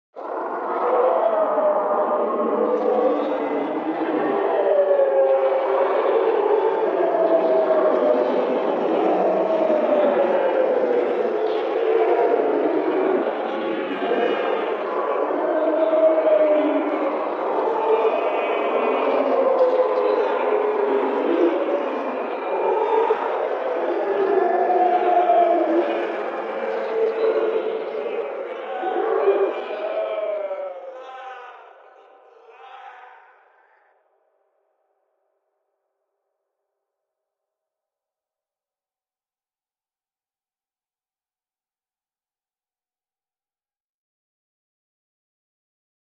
Звуки страшные, жуткие
Адские звуки